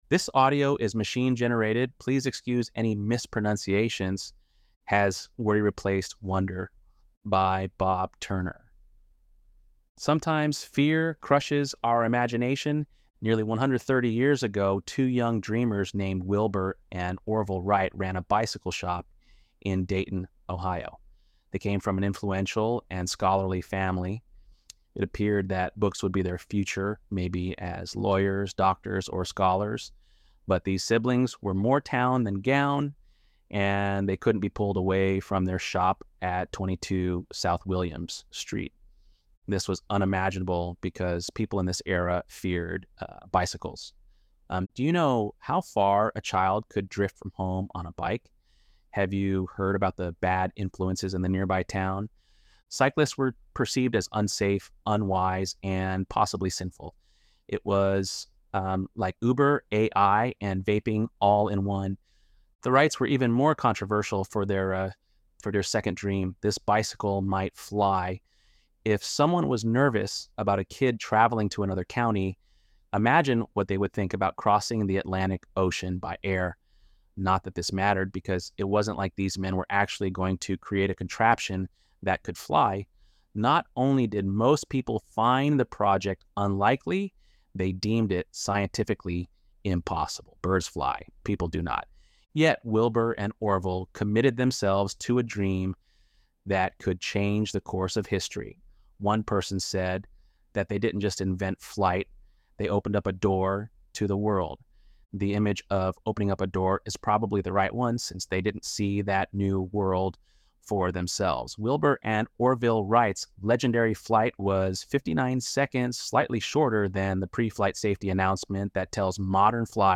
ElevenLabs_7.19_Wond.mp3